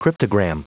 Prononciation du mot cryptogram en anglais (fichier audio)
Prononciation du mot : cryptogram